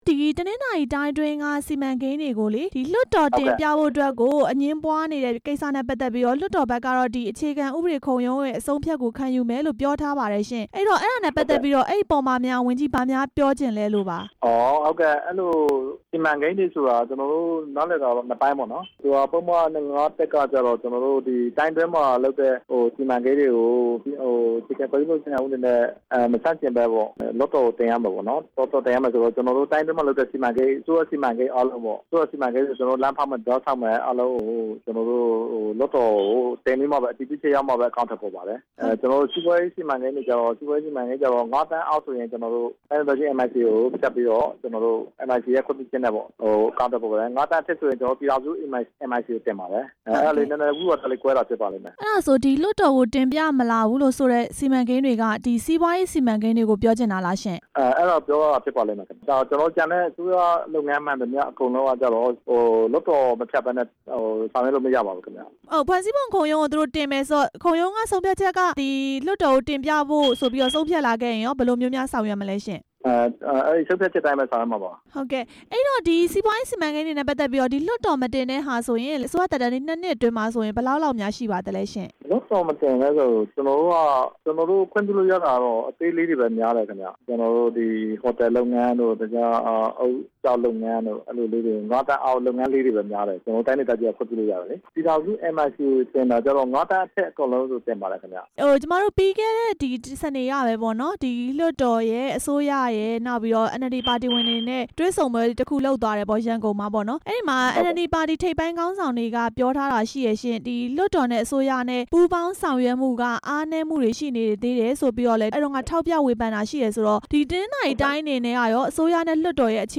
တနင်္သာရီတိုင်း စီမံဘဏ္ဍာဝန်ကြီး ဦးဖြိုးဝင်းထွန်းနဲ့ မေးမြန်းချက်